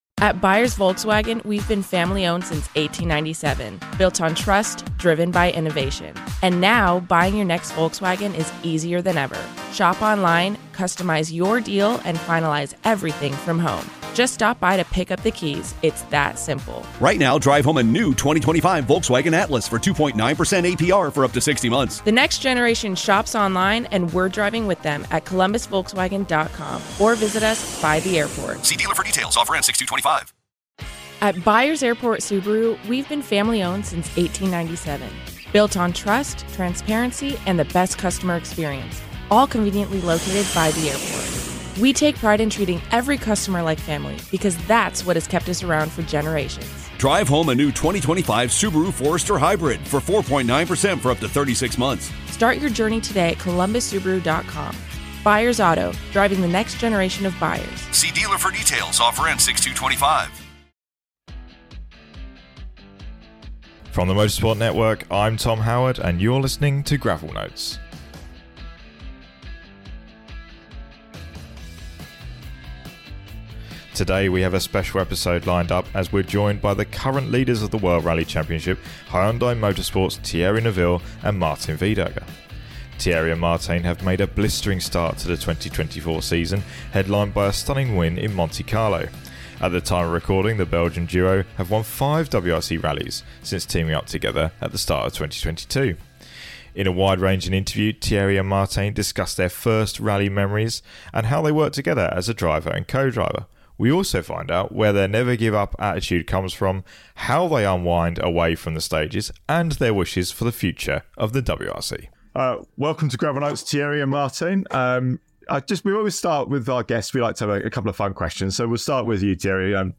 Interview: Thierry Neuville and Martijn Wydaeghe
World Rally Championship points leaders Thierry Neuville and Martijn Wydaeghe join Gravel Notes for a special episode.